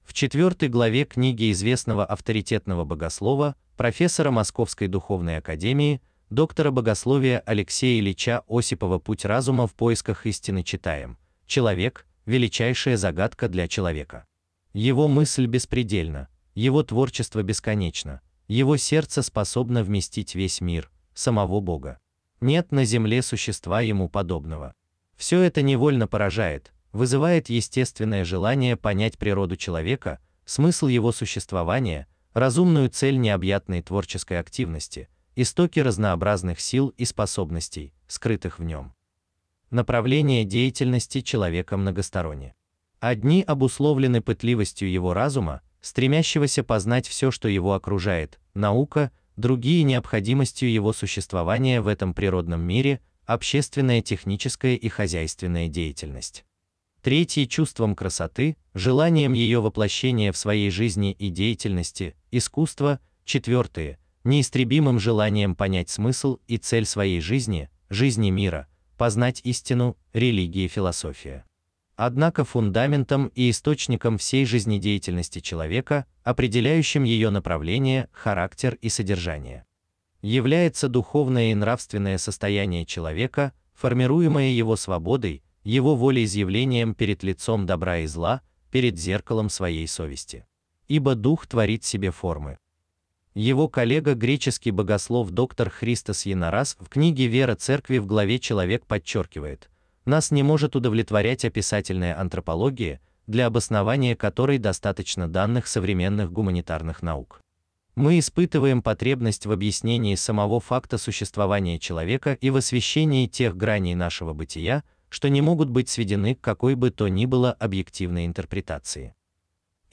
Аудиокнига Сборник святоотеческого, богословского и религиозно-философского комментария по православной христианской антропологии.